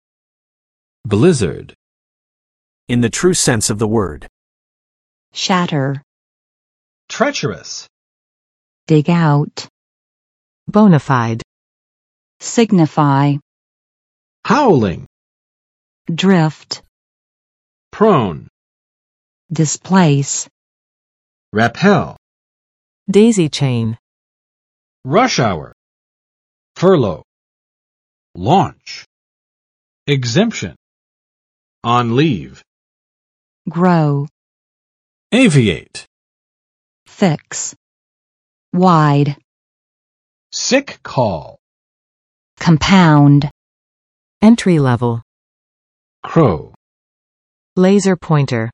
[ˋblɪzɚd] n. 大风雪，暴风雪
blizzard.mp3